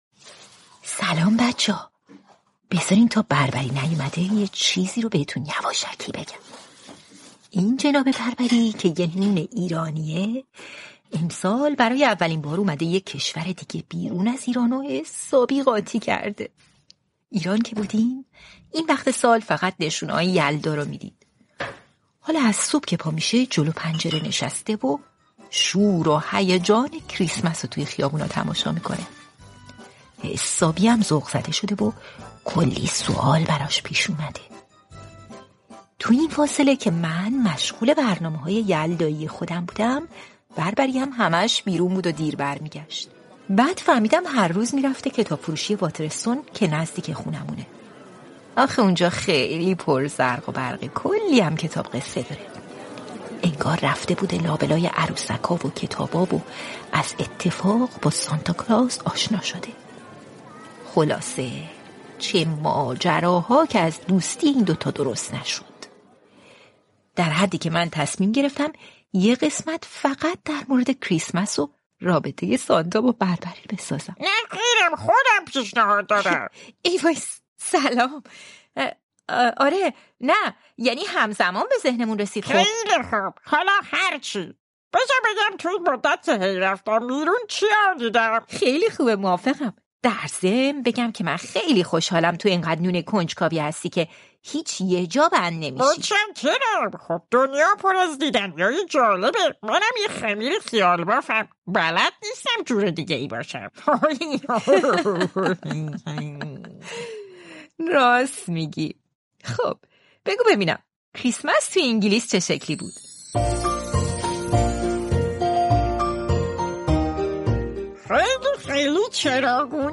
علاوه بر این من تصمیم گرفتم اولین شعری رو که تمام تصاویر امروزی کریسمس به اون برمی‌گرده به فارسی برگردوندم و بصورت آهنگین براتون بخونم.